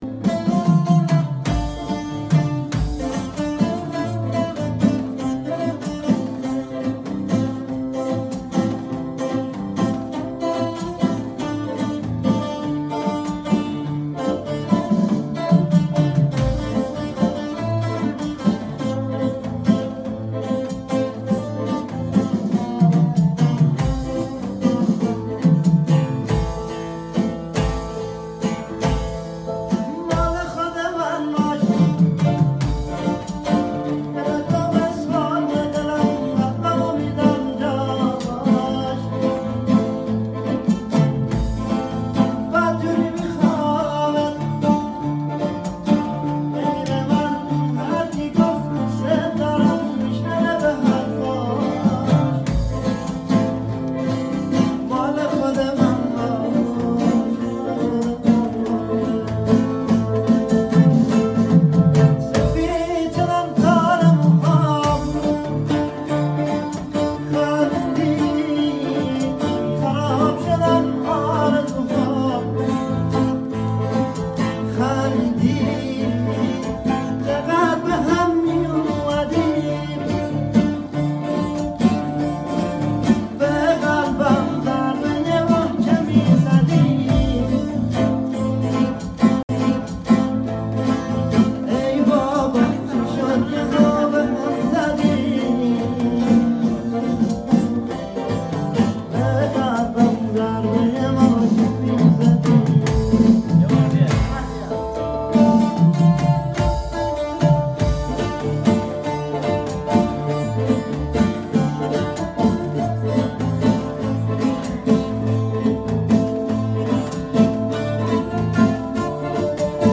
” با گیتار سه تار تنبور غمگین ”